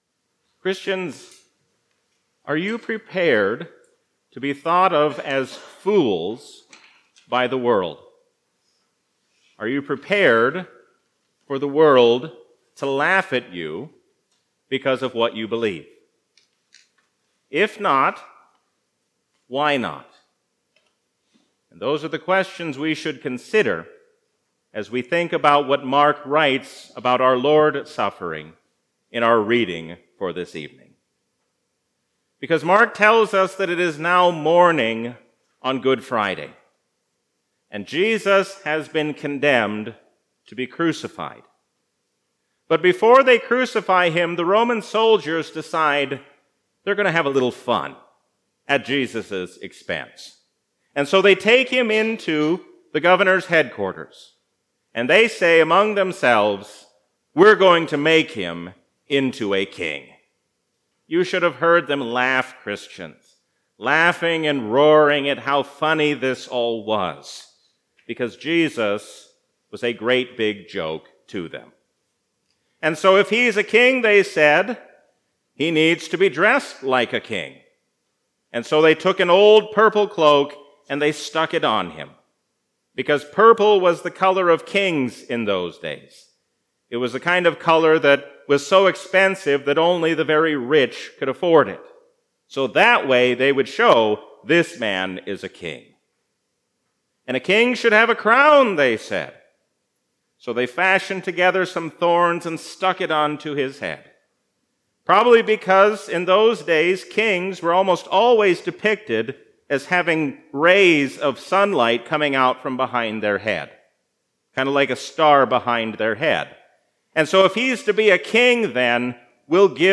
A sermon from the season "Lent 2022."